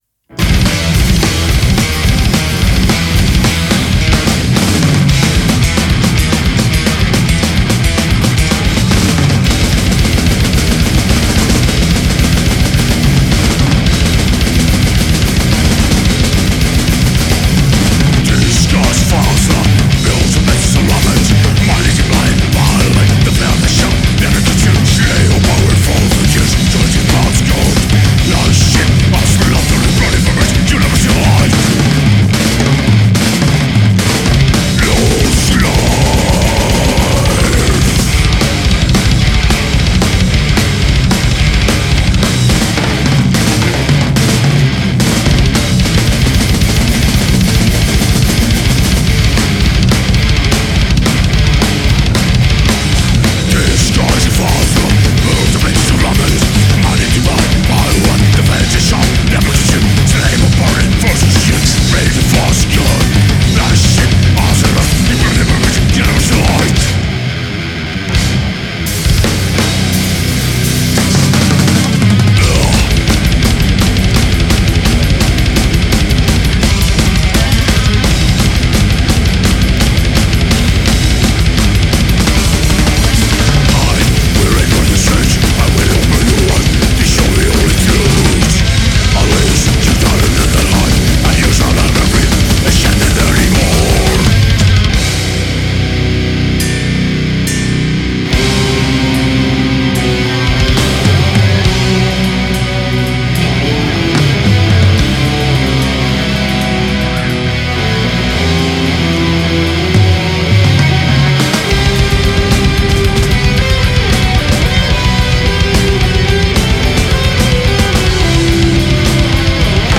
Genre: deathmetal.